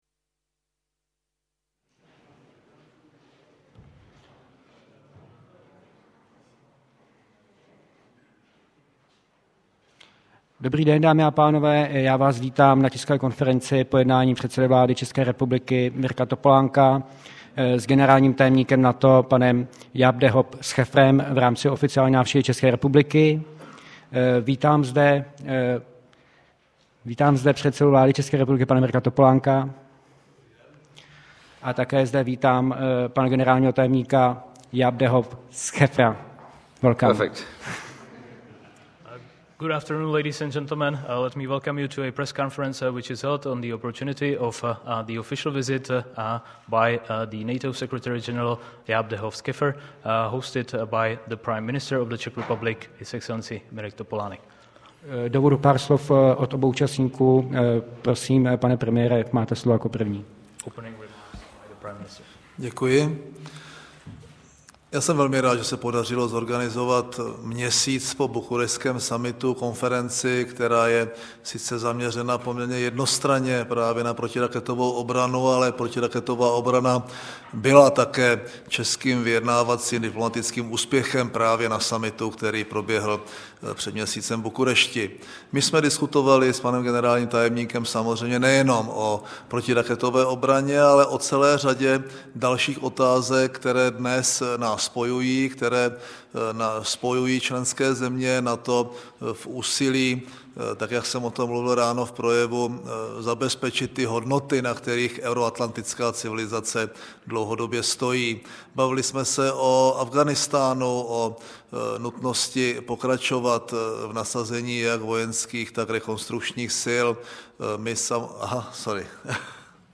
Tisková konference po přijetí generálního tajemníka NATO Jaap de Hoop Scheffera premiérem Mirkem Topolánkem 5.5.2008